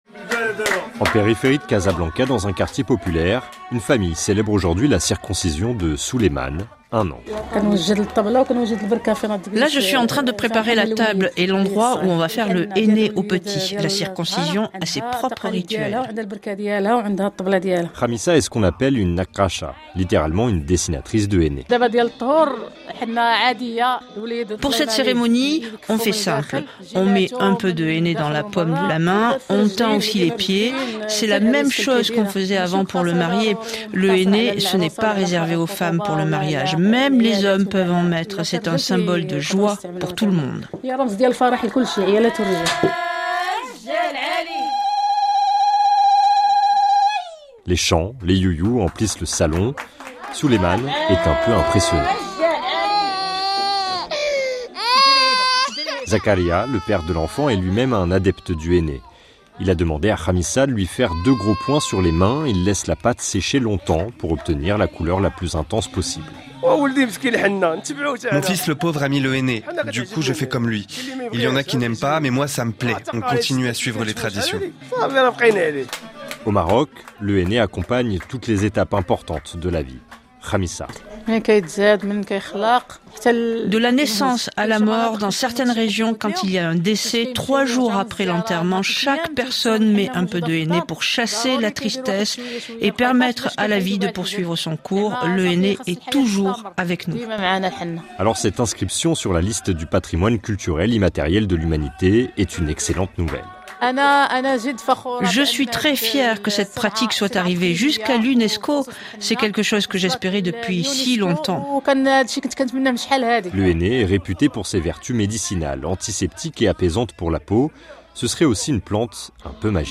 De notre correspondant à Casablanca,
Les chants et les youyous emplissent le salon.